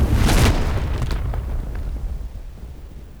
Crash sound was way too loud, Portal also felt slightly annoying - crash: -7dB - portal: -3dB 2024-01-07 21:47:26 +01:00 277 KiB Raw Permalink History
crash_rock-cinematic.wav